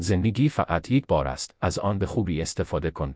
persian-tts-male1-vits-coqui/test.wav